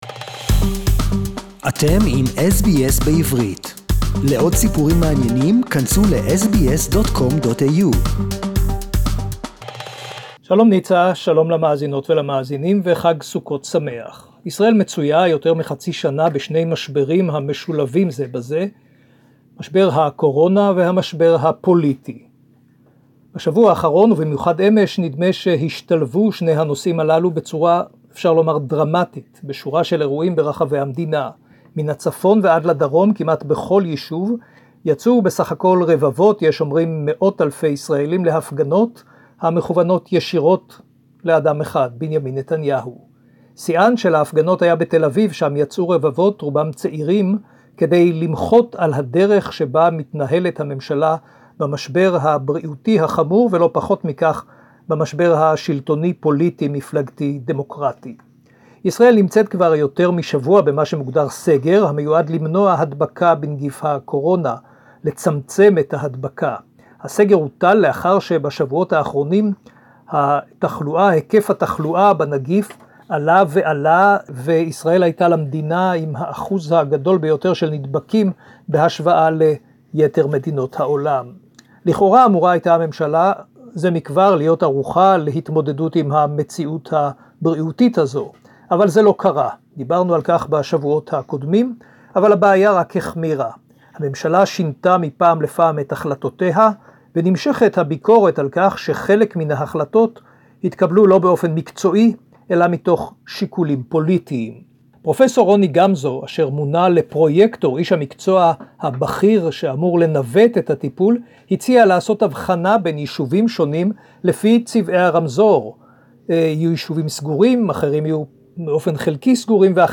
Pandemic Crisis as rallies break out across Israel, SBS Jerusalem Report in Hebrew